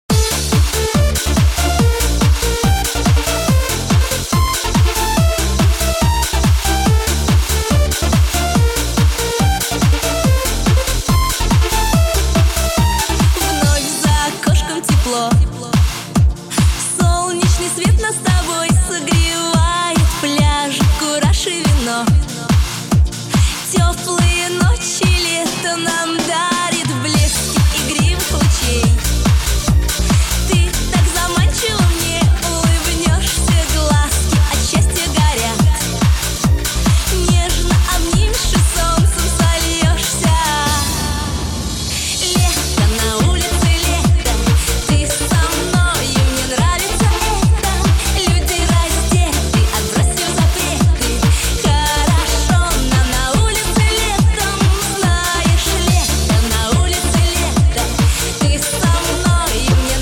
Pop
лучшие образцы танцевальной музыки